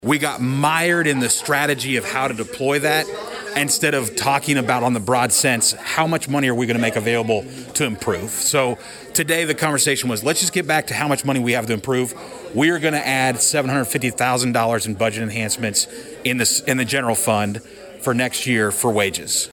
Mayor Rob Gilligan says the city’s current plan of adding additional enhancement for salaries “was always the plan.”